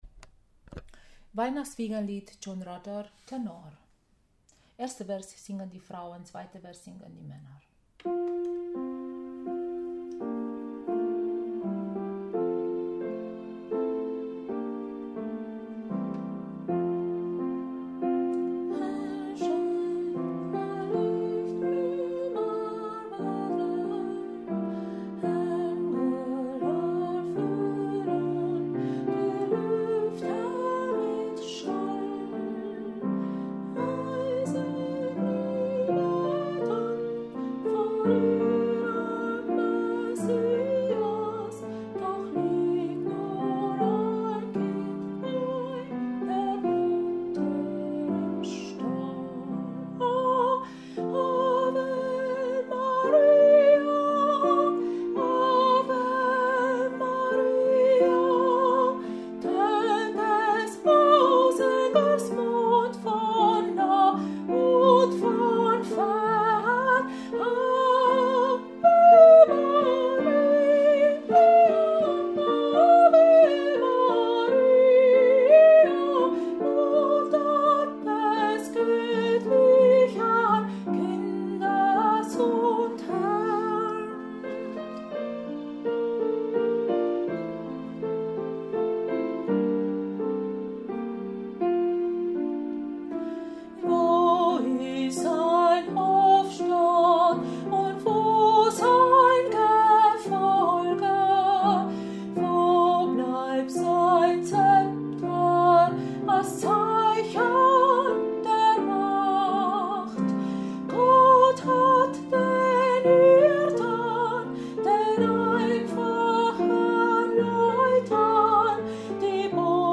John Rutter Weihn.-Wiegenlied Tenor